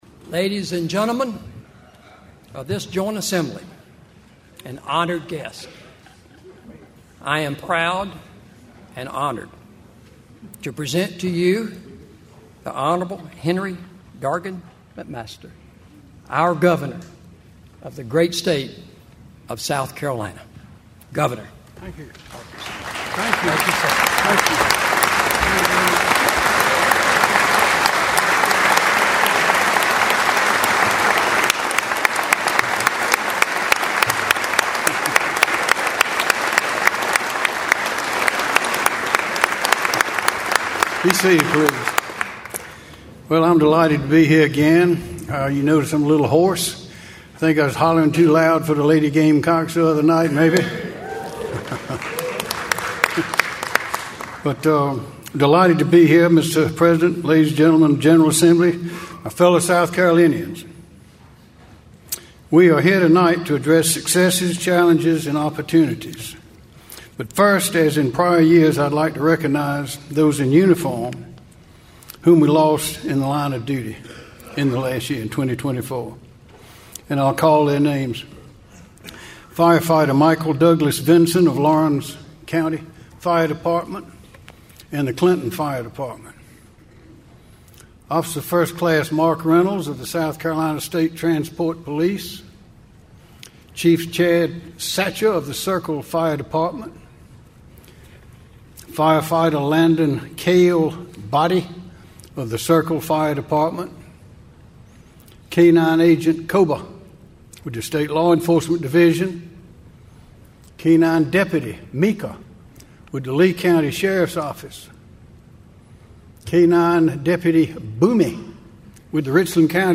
Gov Henry McMaster gave his 8th State of the State address Wednesday, and the theme was about making investments. McMaster asked lawmakers to put hundreds of millions of dollars towards fixing South Carolina’s roads and bridges, paying law enforcement more and helping the state recover from Helene.
Here is the Governor’s address in it’s entirety.